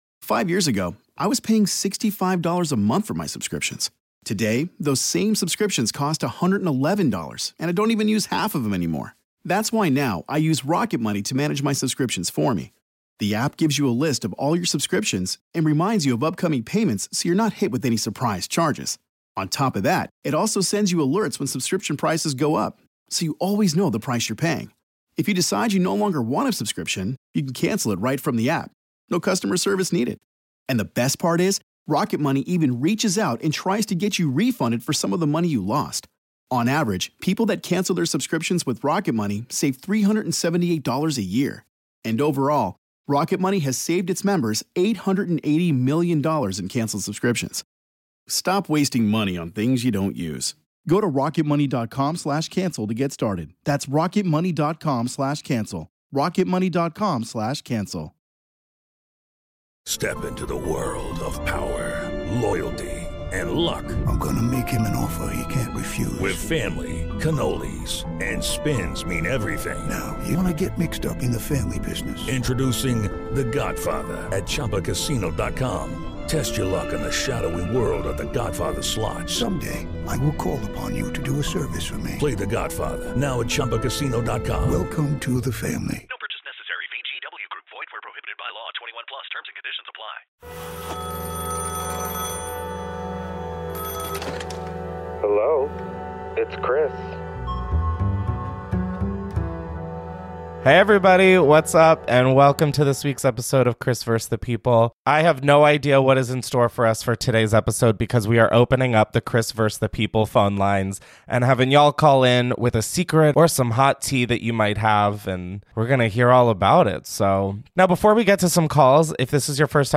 One caller shares how her ex–best friend accused her of being an unfit mother and threatened CPS only for that same friend’s toddler to later wander outside alone in the snow. Another listener admits to catfishing her middle school bullies just to figure out why they hated her (honestly? valid).